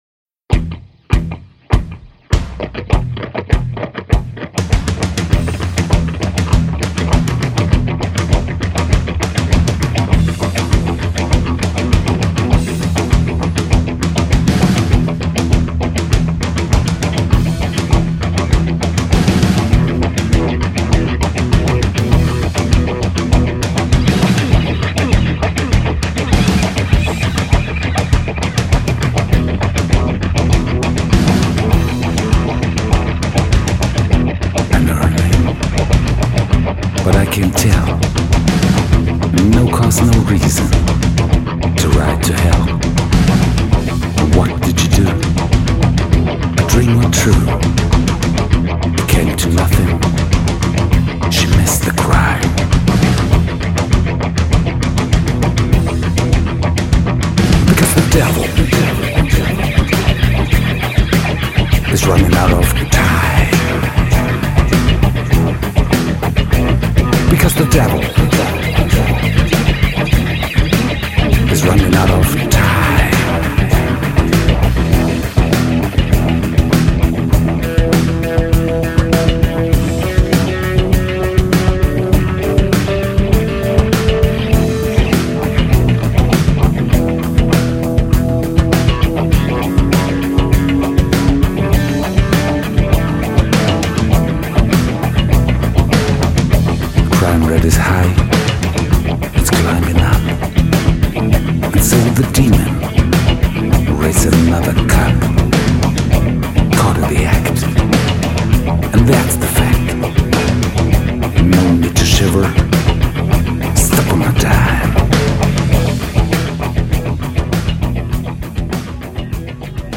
Straight from the heart and dynamic